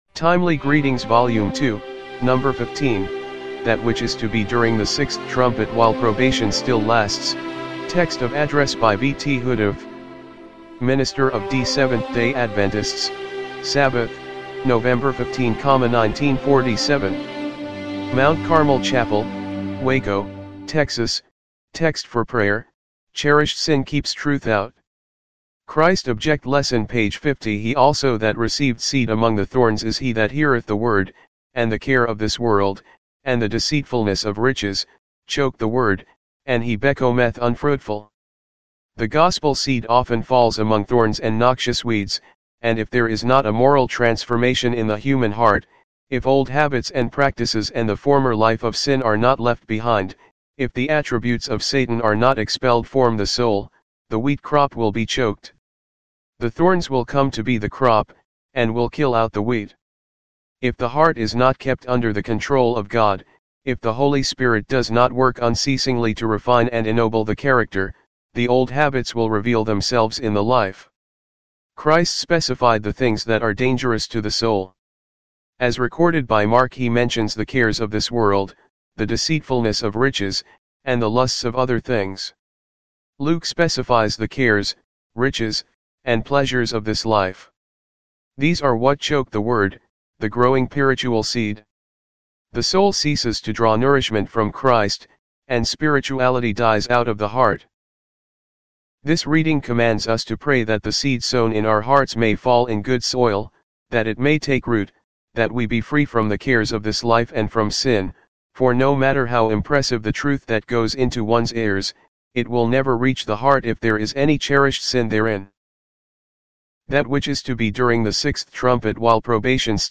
timely-greetings-volume-2-no.-15-mono-mp3.mp3